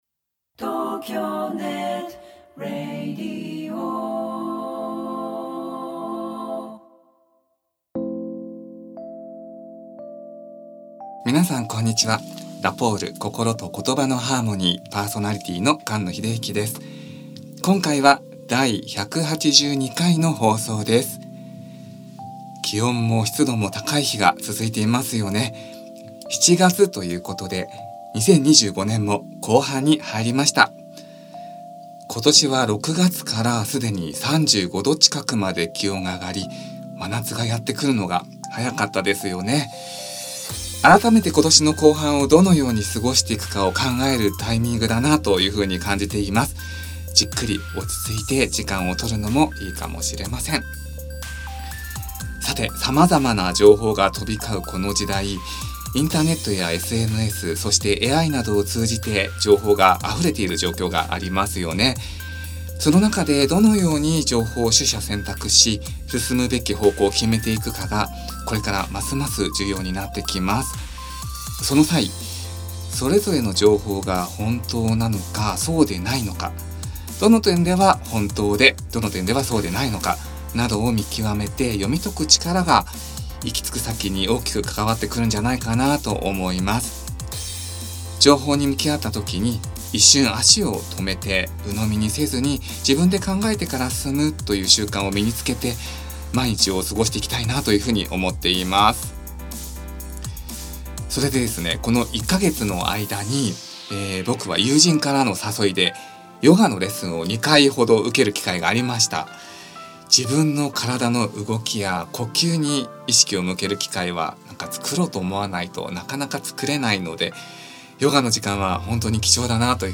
限りない癒しの波動で感動と安らぎをお伝えします。 言葉、音楽、身体表現、ヒーリングに携わるアーティストをゲストに迎え、言葉への想いをトーク・朗読・詩・音楽・星誦み・時誦みとともにお届けしていきます。